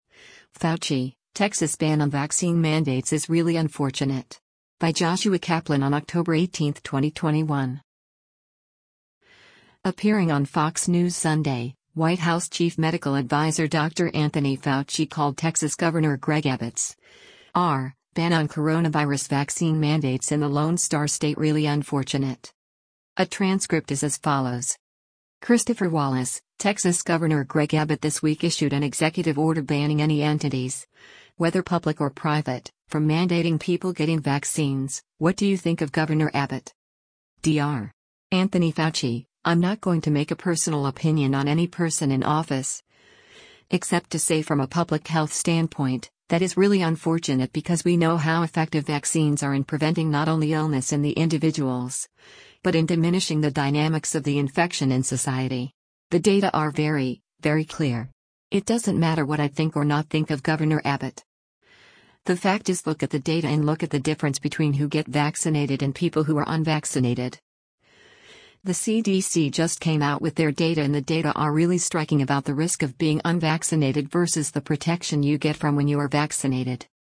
Appearing on Fox News Sunday, White House chief medical adviser Dr. Anthony Fauci called Texas Gov. Greg Abbott’s (R) ban on coronavirus vaccine mandates in the Lone Star State “really unfortunate.”